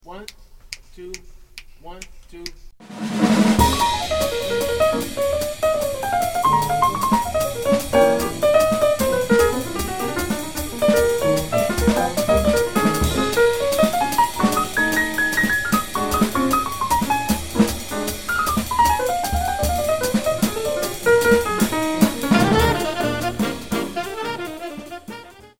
fun medium-up blues head